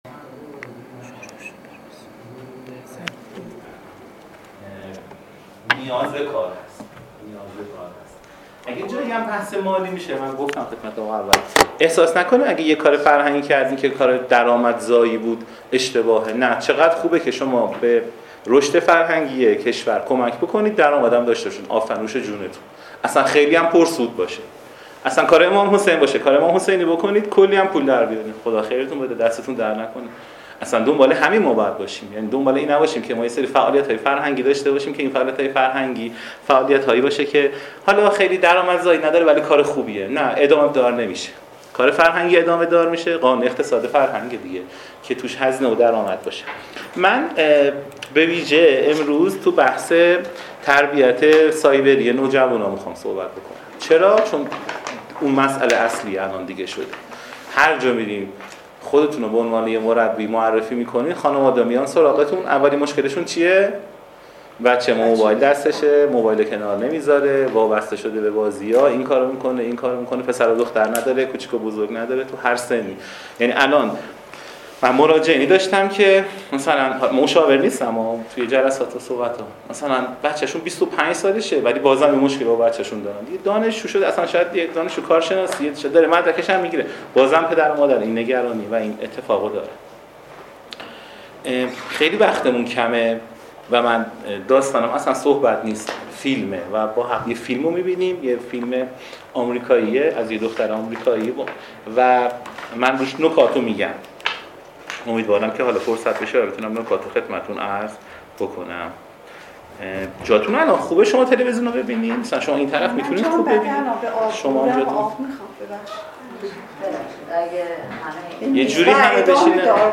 کارگاه تخصصی فرهنگ و رسانه ویژۀ مدیران فرهنگی